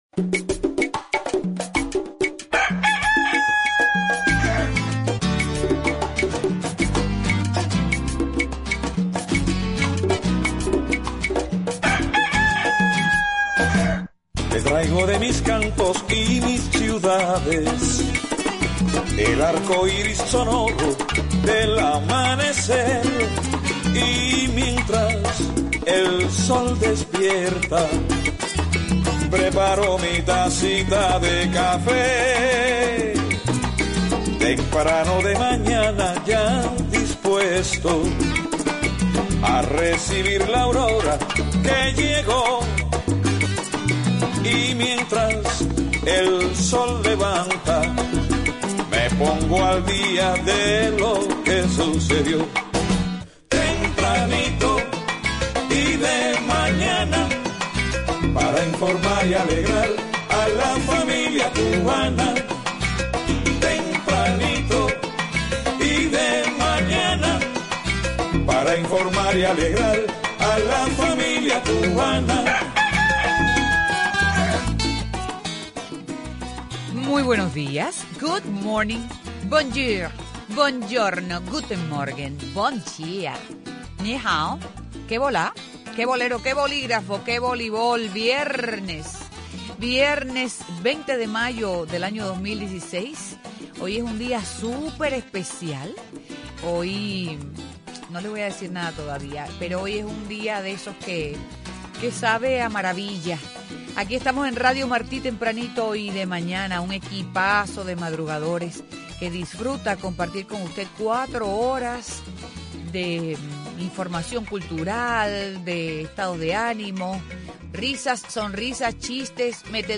Una celebración única, con entrevistas de fundadores de la emisora, personalidades, empleados y la participación de los oyentes a través del teléfono y el Internet.